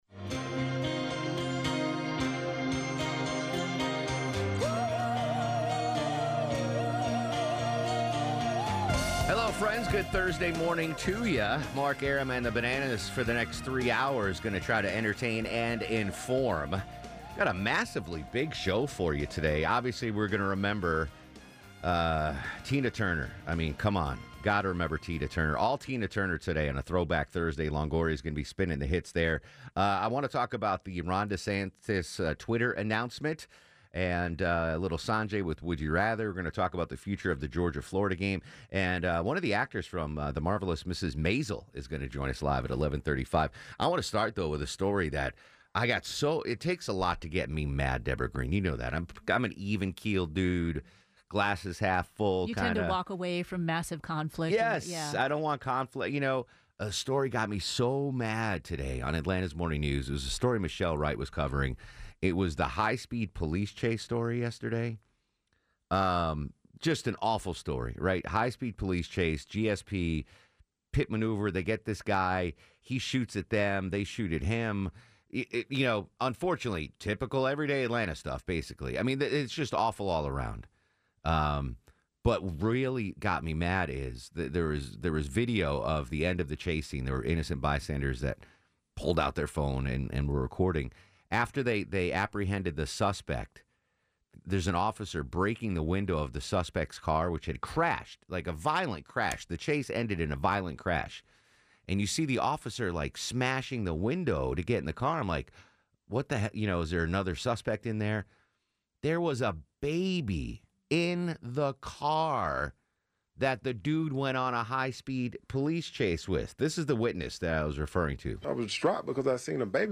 95.5 WSB Atlanta radio show